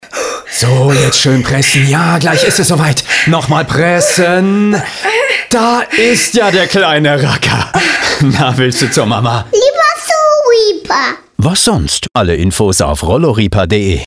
Die Einleitung zum neuen Radiospot von Rollo Rieper ist geboren. Ein Frau stöhnt angestrengt. Dann kommt der Arzt aus dem Hintergrund und ruft: „Pressen, pressen!“